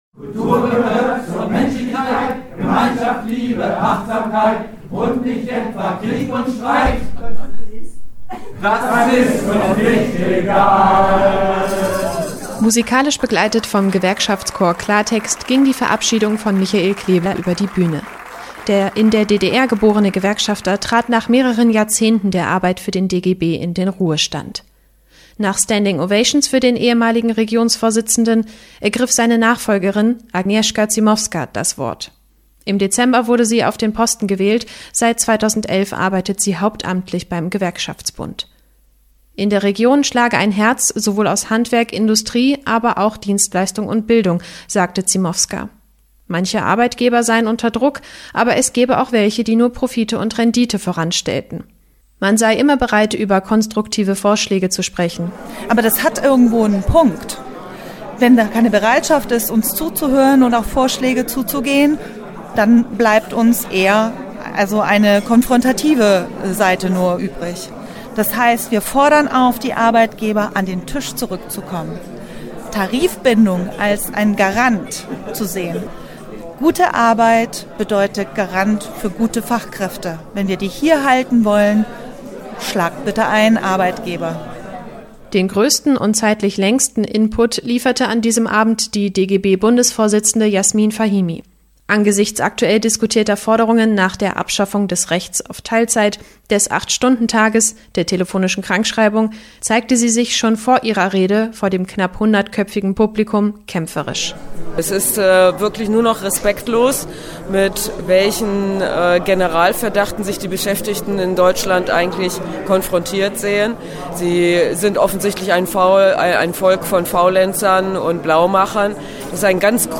Herausfordernde Zeiten: Neujahrsempfang des DGB in Braunschweig - Okerwelle 104.6